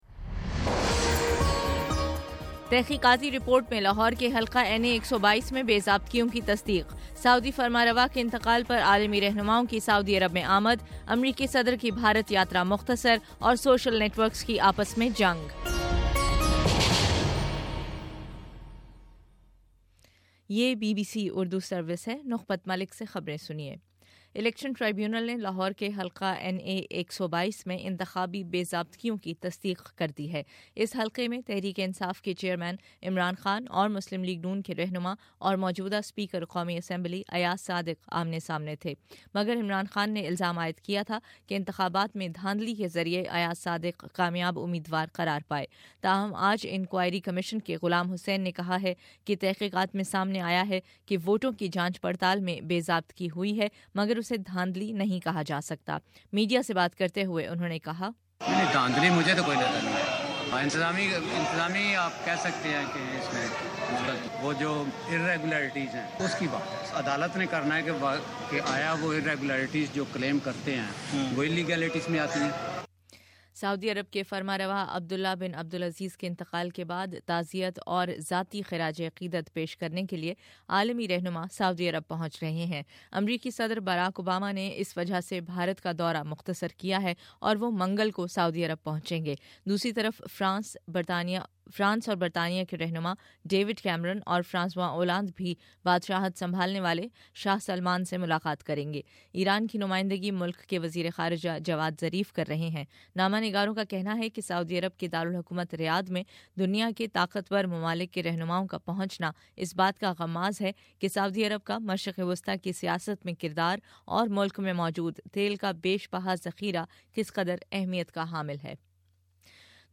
جنوری 24: شام چھ بجے کا نیوز بُلیٹن